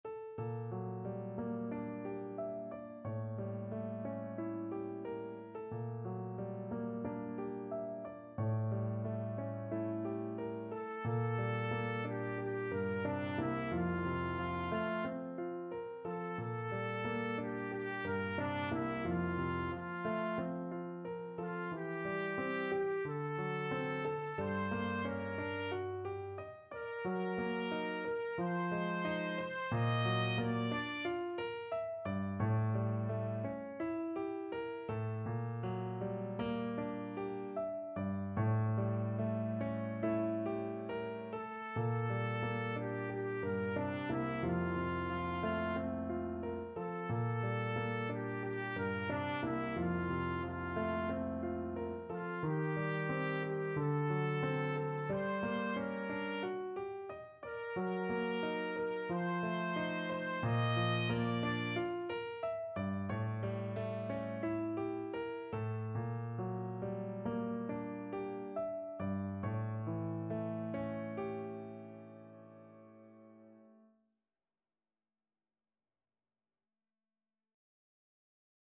Trumpet version
Score Key: F major (Sounding Pitch)
Time Signature: 2/4
Langsam, zart